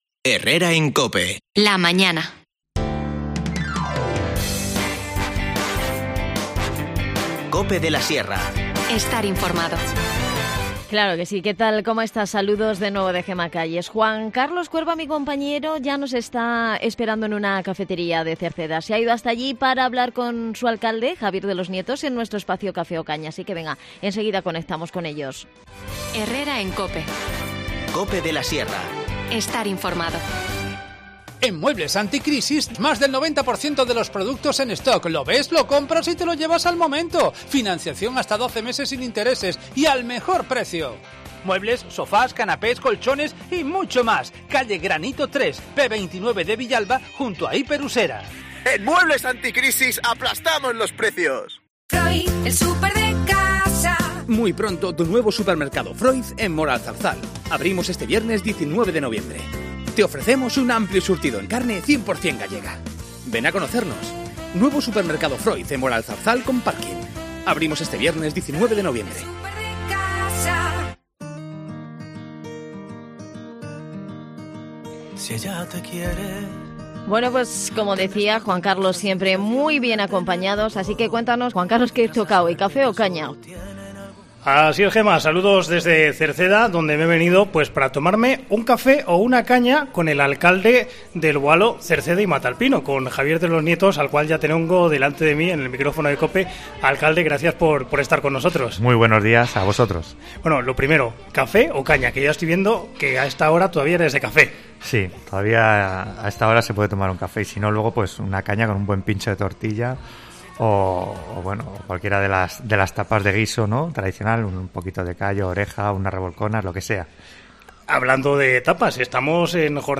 AUDIO: Este miércoles 17 de noviembre charlamos con Javier de los Nietos, alcalde de El Boalo, Cerceda y Mataelpino en nuestro espacio...